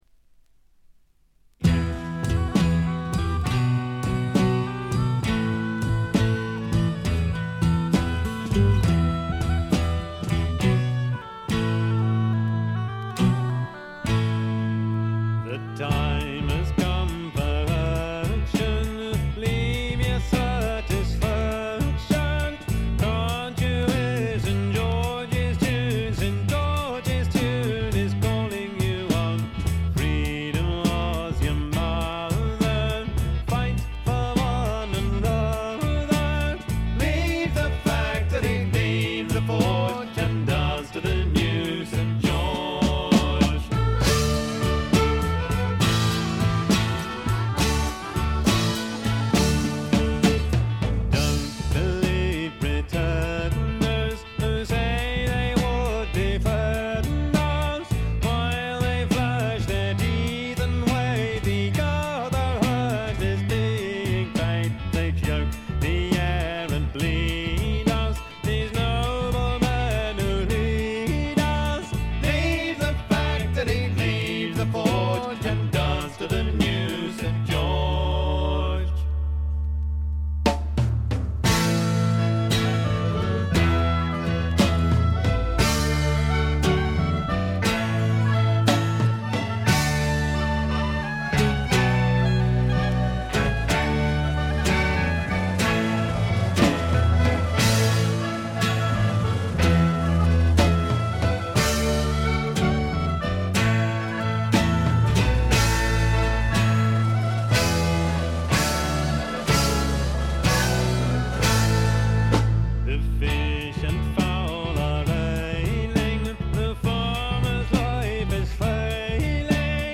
エレクトリック・トラッドの基本中の基本です。
試聴曲は現品からの取り込み音源です。